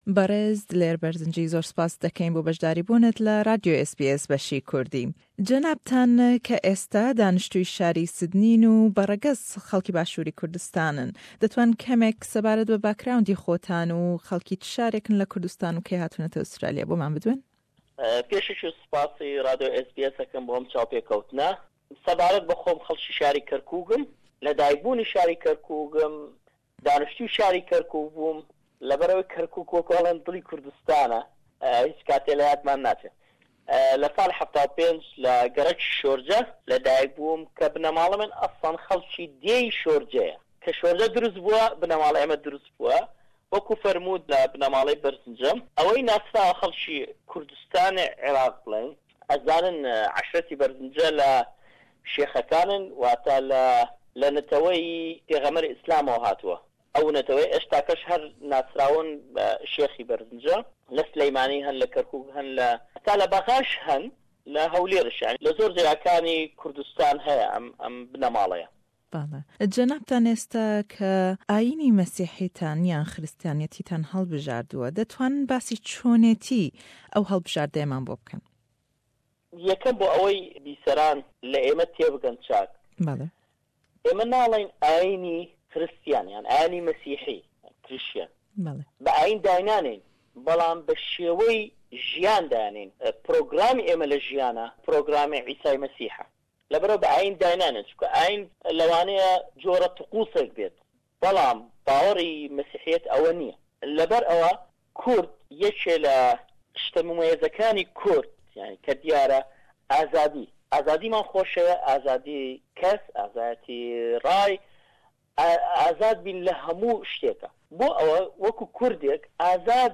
Beşî yek û dû le hevpeyvînman